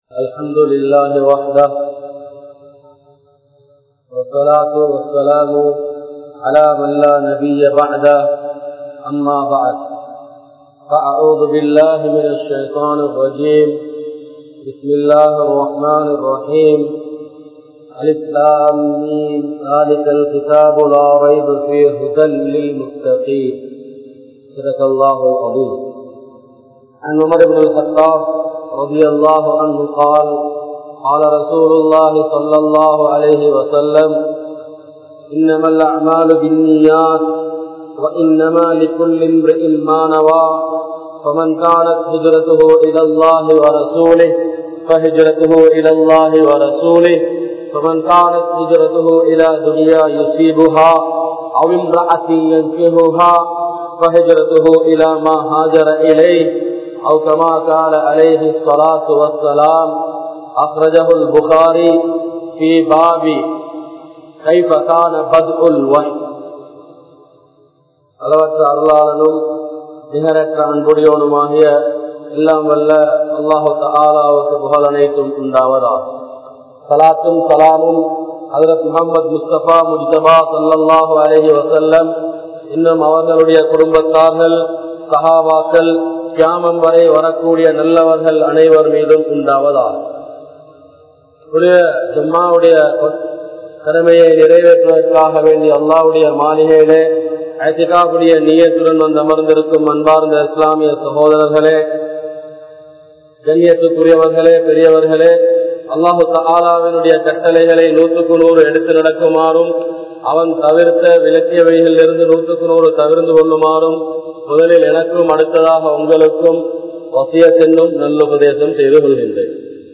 Humanity in Islam (இஸ்லாத்தில் மனிதநேயம்) | Audio Bayans | All Ceylon Muslim Youth Community | Addalaichenai
Colombo 12, Meeraniya Jumua Masjith